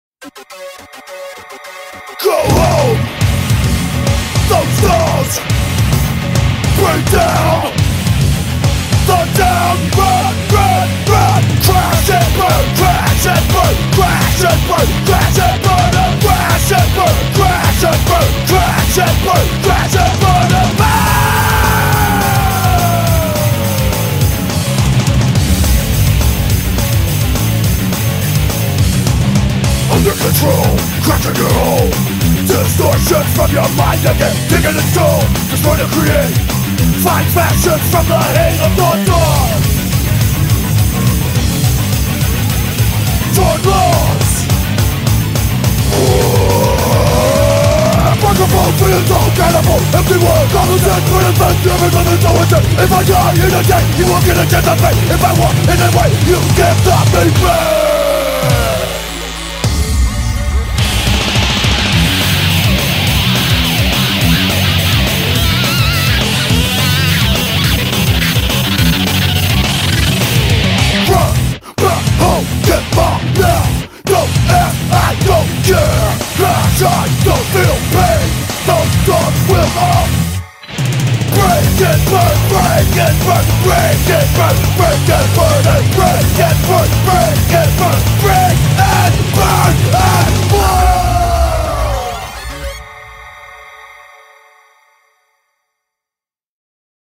BPM210-420
Audio QualityPerfect (Low Quality)